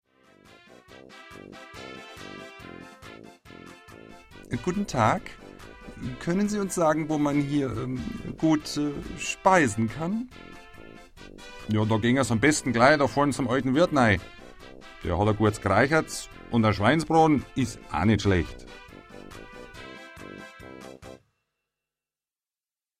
deutscher Sprecher
Sprechprobe: eLearning (Muttersprache):
german voice over talent